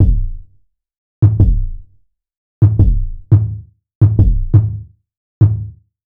Kick - ny (edit).wav